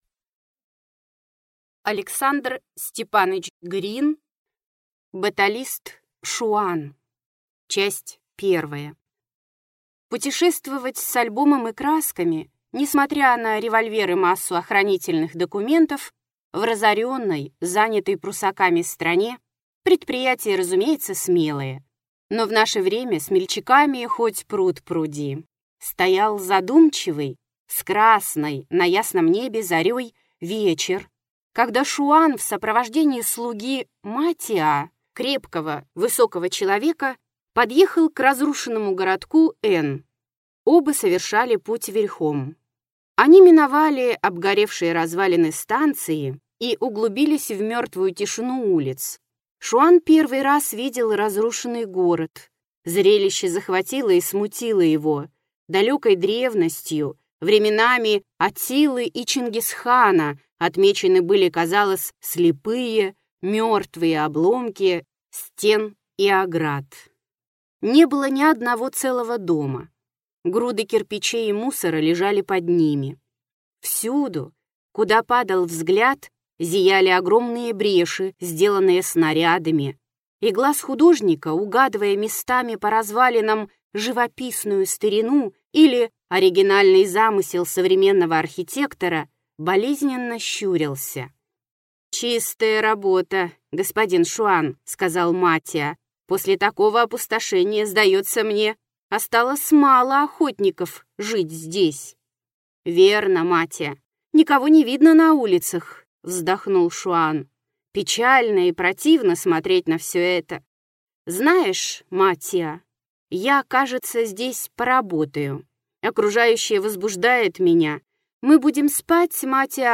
Аудиокнига Рассказы (Баталист Шуан, Безногий, Пропавшее солнце, Рене, Убийство в Кунст-Фише) | Библиотека аудиокниг